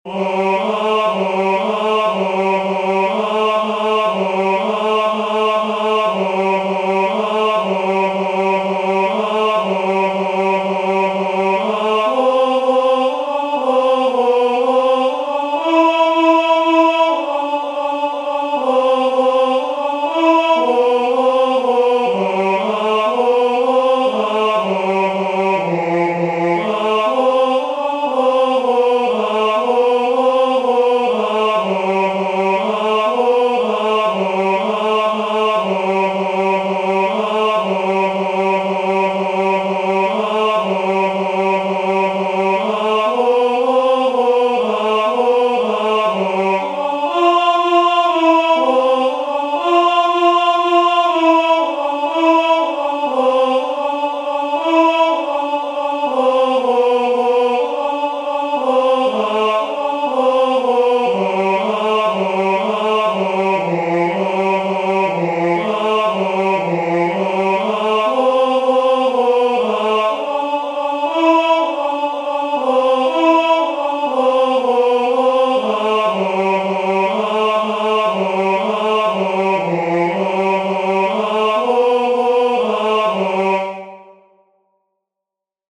"Beati estis," the second responsory from the second nocturn of Matins, Common of Apostles